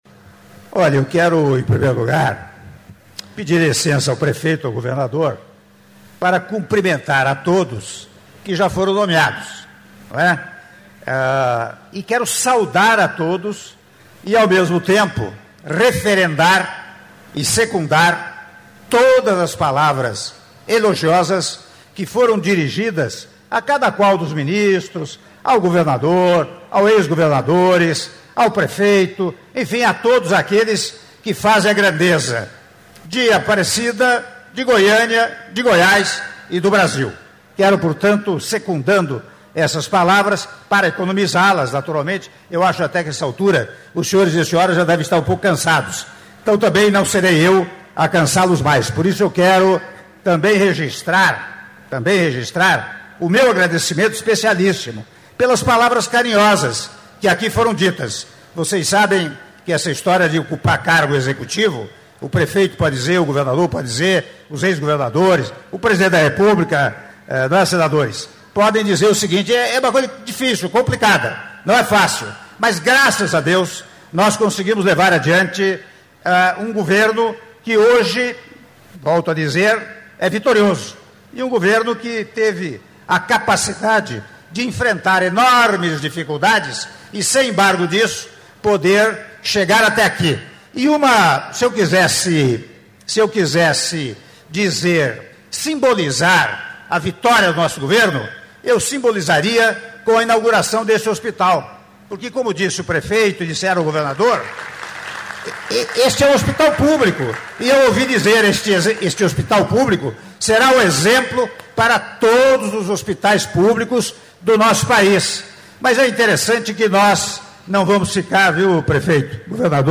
Áudio do discurso do Presidente da República, Michel Temer, durante Cerimônia de Inauguração do Hospital Municipal de Aparecida de Goiânia - Aparecida de Goiânia/GO (07min10s)